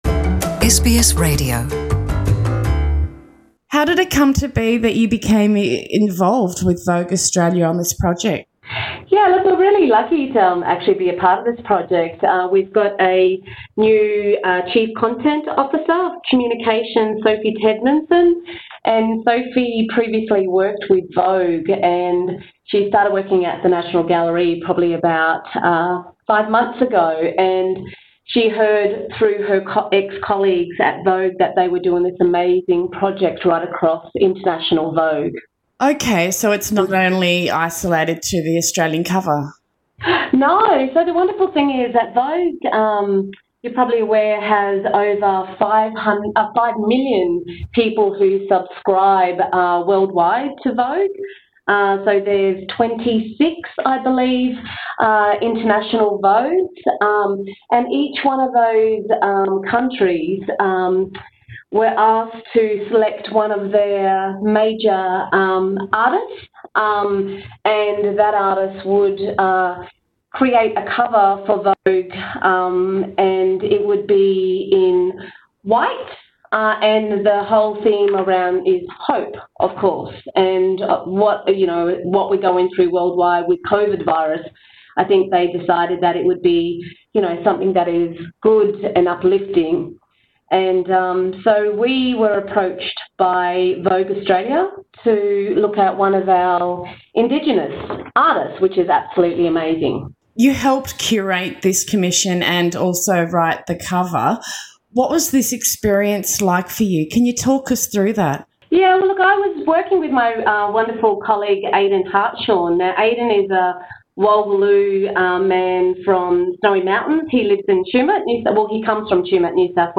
Listen to our NITV radio interview